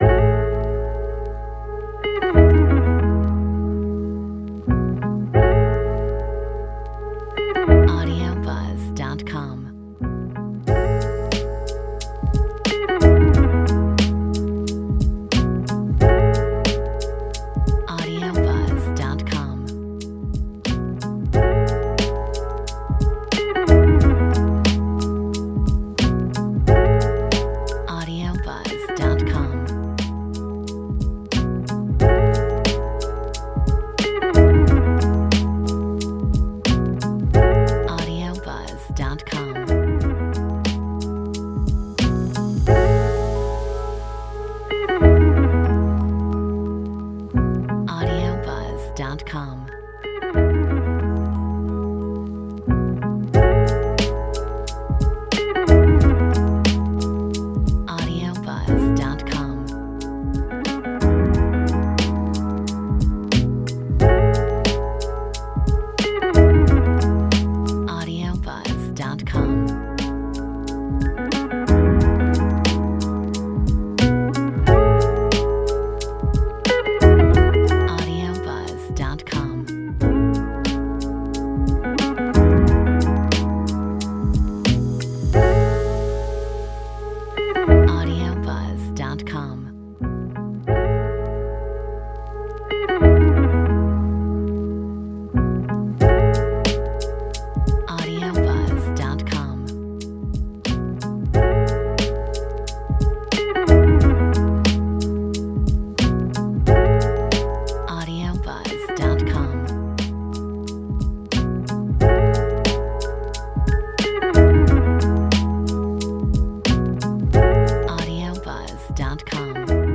Metronome 90